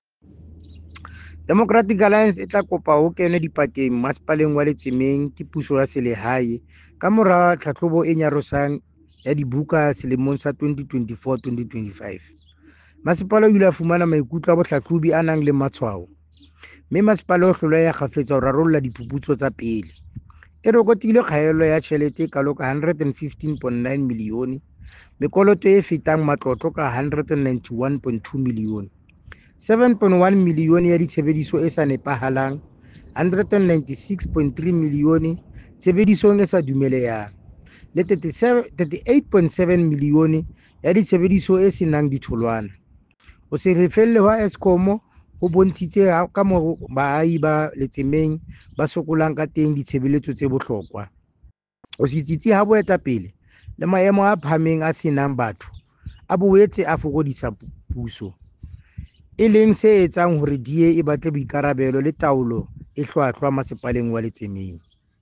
Sesotho soundbites Cllr Thabo Nthapo and